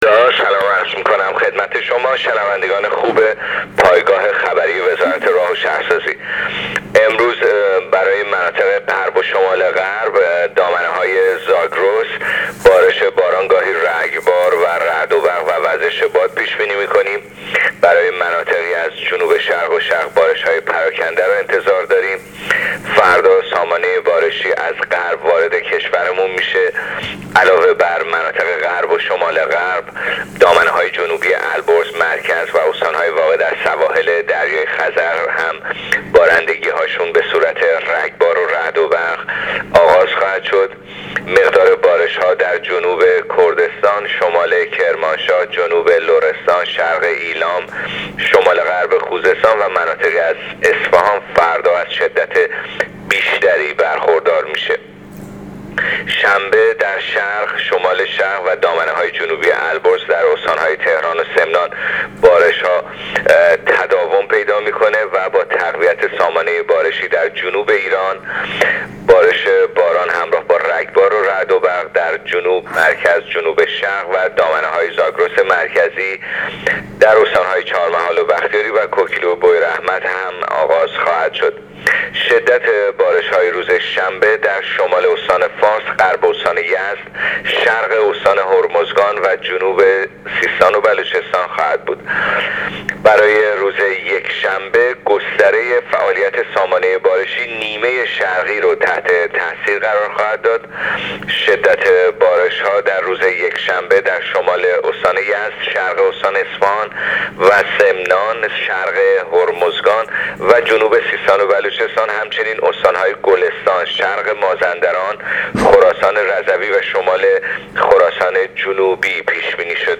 گزارش رادیو اینترنتی وزارت راه و شهرسازی از آخرین وضعیت آب و هوا در بیست و دومین روز سال ۱۳۹۸/بارش باران و وزش باد در غرب و شمالغرب و دامنه‌های زاگرس/ورود سامانه بارشی از غرب به کشور طی فردا/شدت بارش های فردا در خوزستان، ایلام، کرمانشاه، لرستان، کردستان و اصفهان